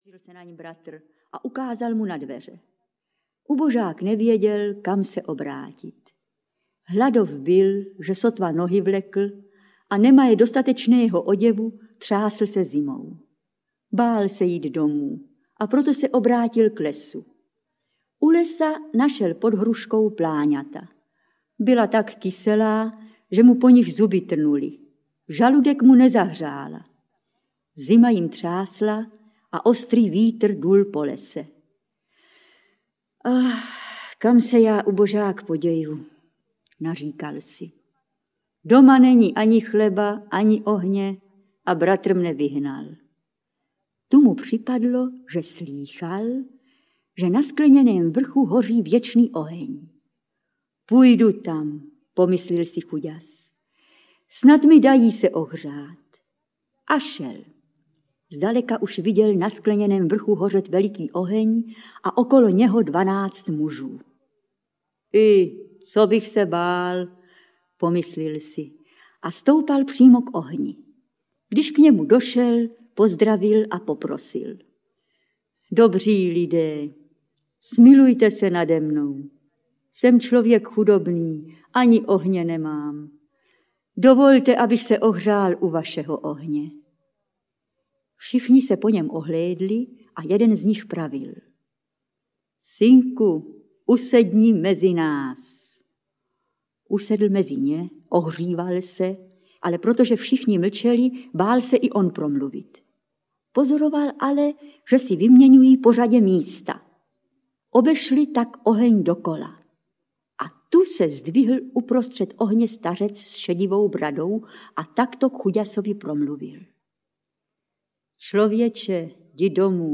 Audiokniha Pohádky Boženy Němcové s dramatizovanými i vyprávěnými pohádkami.